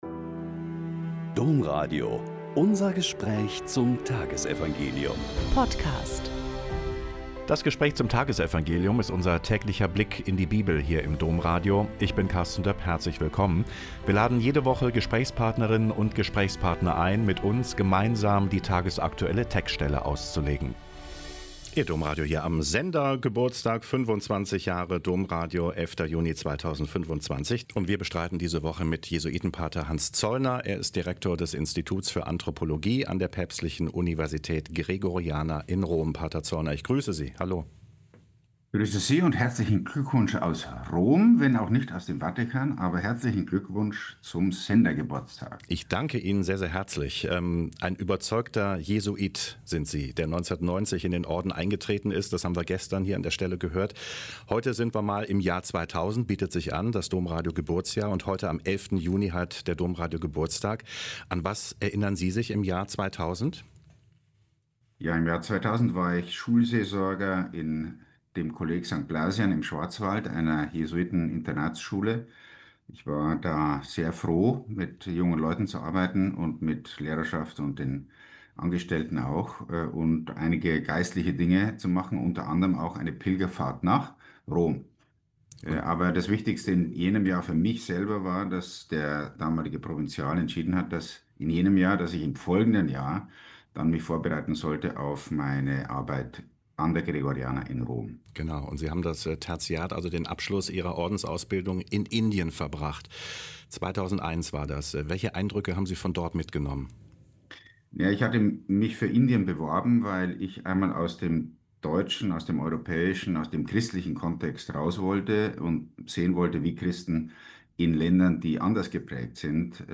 Mt 5,17-19 - Gespräch mit Pater Hans Zollner SJ